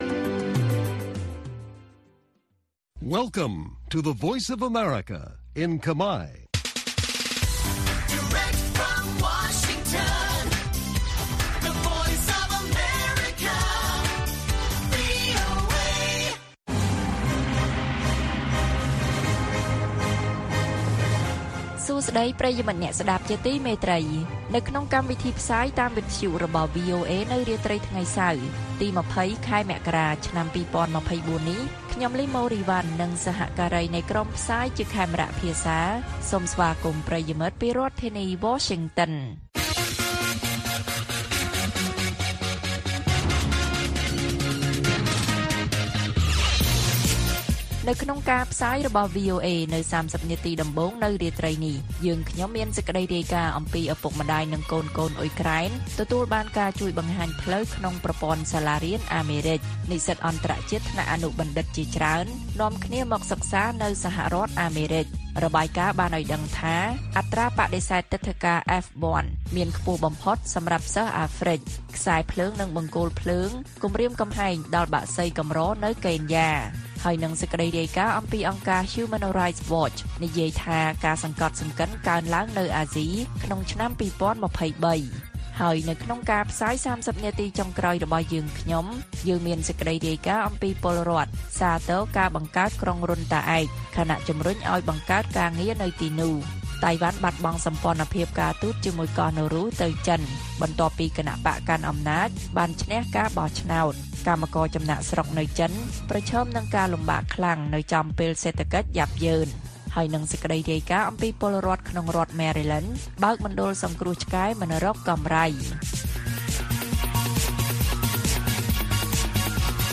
ព័ត៌មានពេលរាត្រី ២០ មករា៖ ឪពុកម្តាយនិងកូនៗអ៊ុយក្រែនទទួលបានការជួយបង្ហាញផ្លូវក្នុងប្រព័ន្ធសាលារៀនអាមេរិក